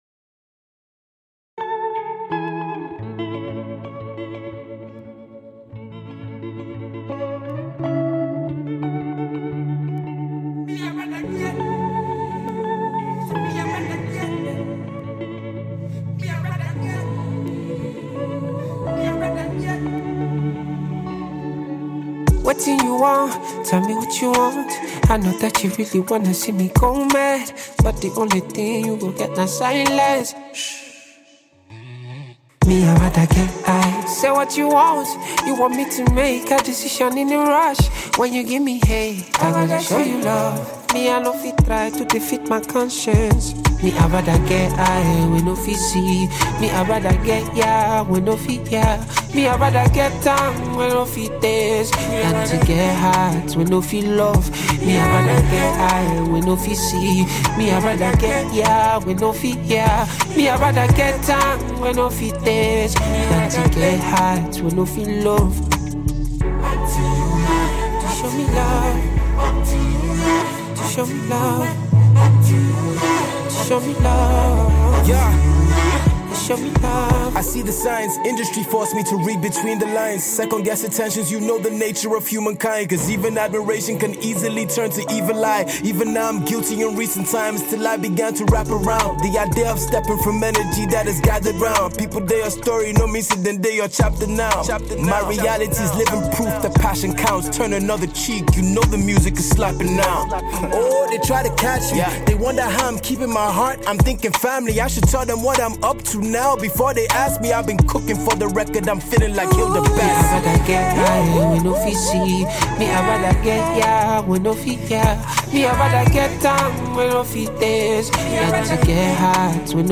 Category: Nigerian / African Music Genre: Afrobeats Released